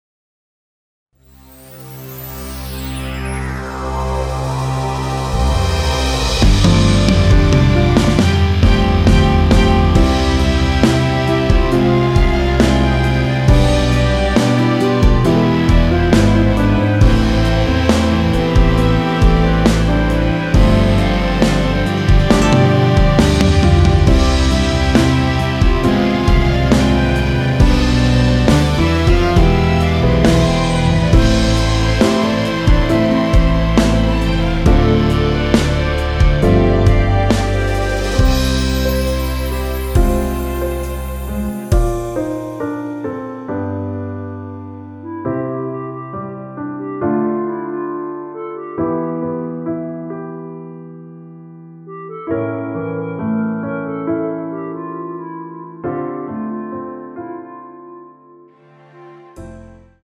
원키에서(-2)내린 멜로디 포함된 MR입니다.(미리듣기 확인)
앞부분30초, 뒷부분30초씩 편집해서 올려 드리고 있습니다.
중간에 음이 끈어지고 다시 나오는 이유는